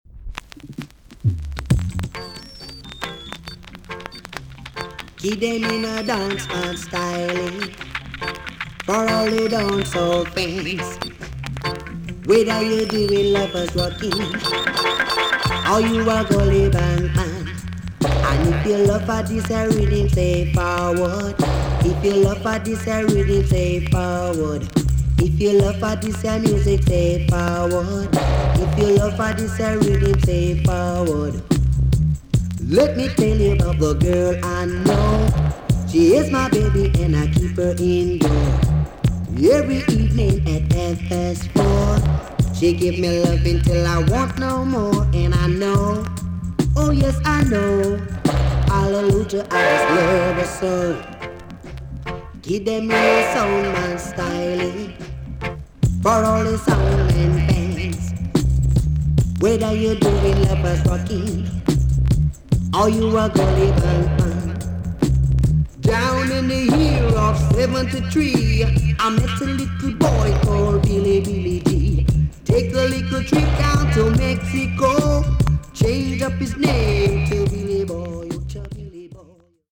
TOP >80'S 90'S DANCEHALL
VG+ 少し軽いチリノイズがあります。